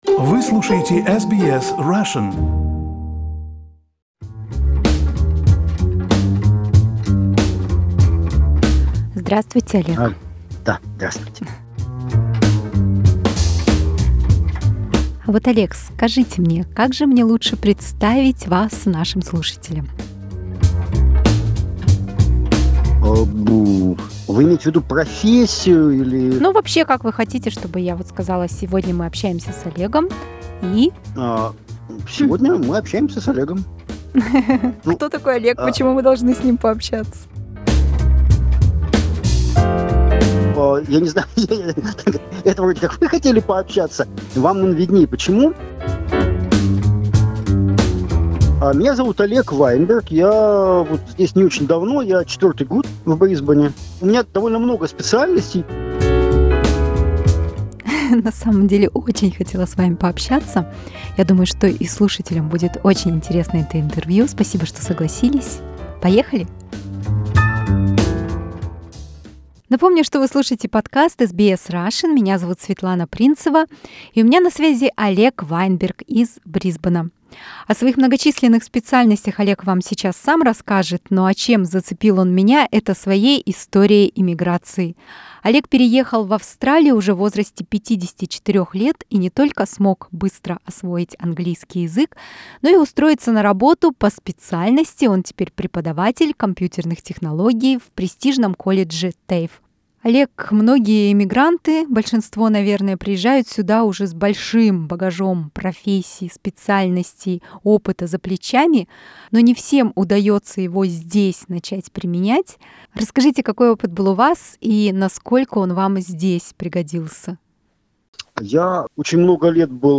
Беседа, как и сам герой материала, получилась очень позитивная и вдохновляющая.